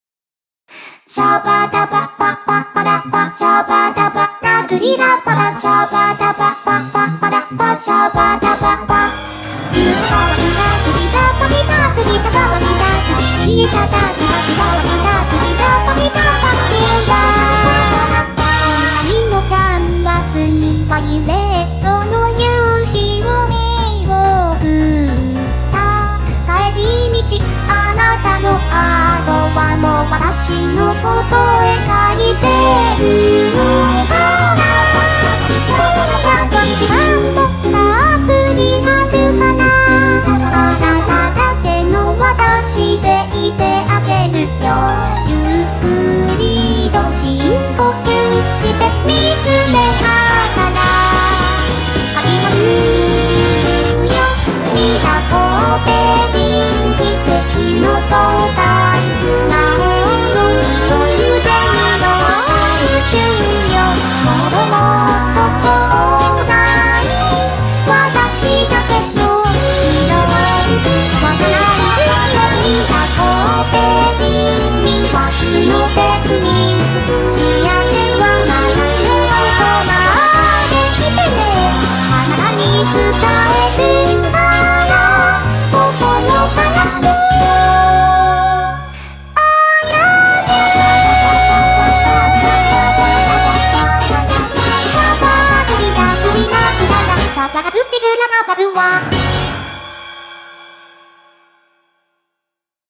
BPM215
Audio QualityPerfect (High Quality)
jazz song